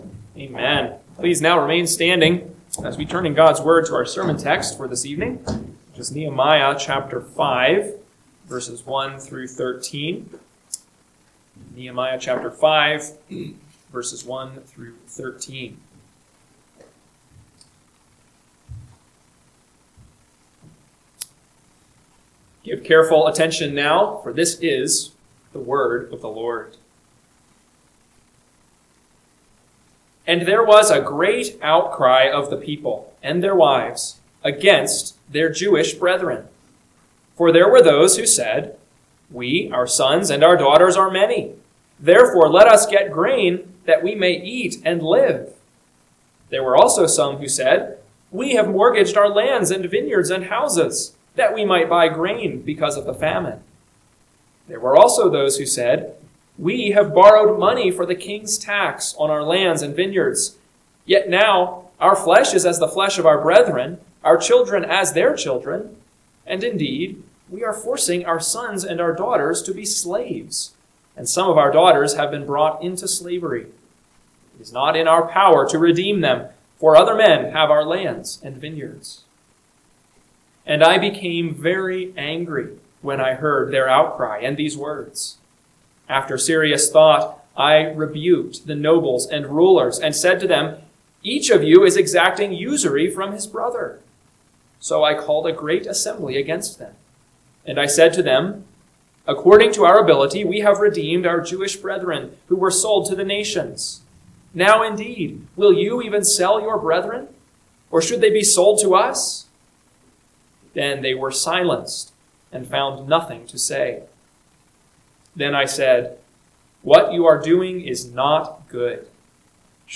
PM Sermon – 8/10/2025 – Nehemiah 5:1-13 – Northwoods Sermons